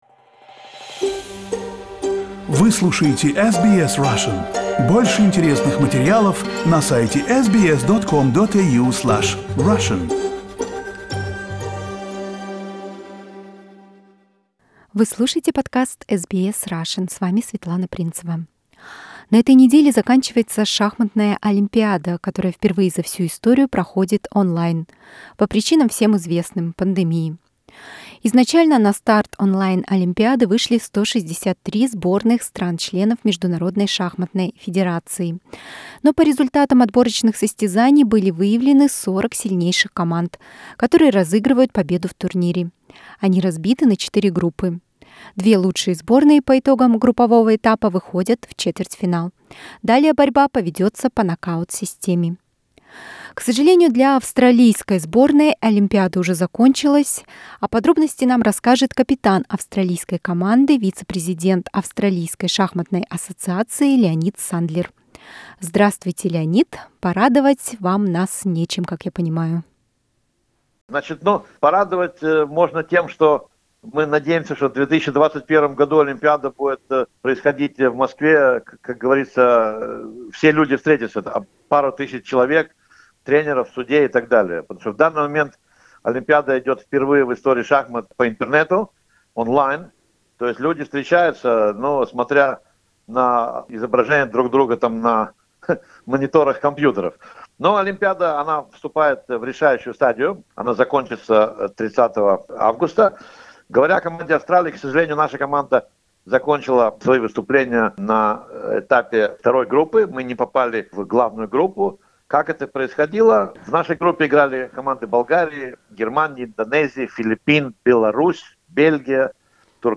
Interview with International Master